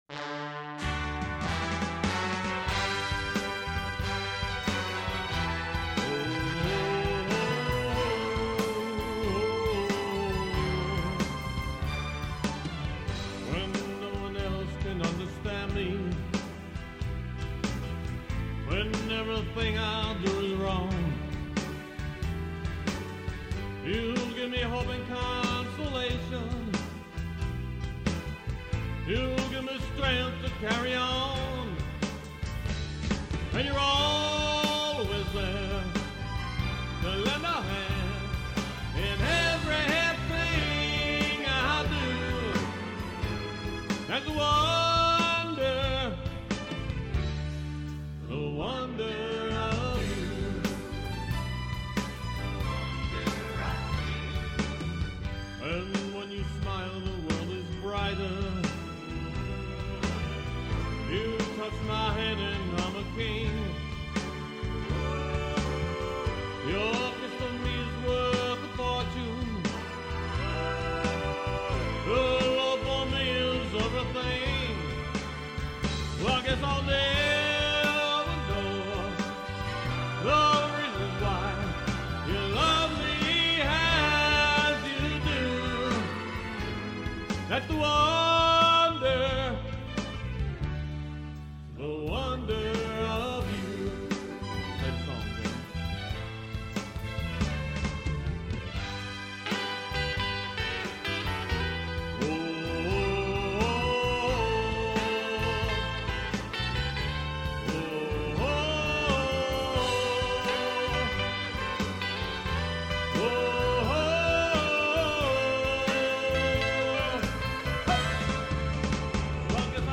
Rock & Roll